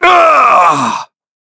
Shakmeh-Vox_Damage_07.wav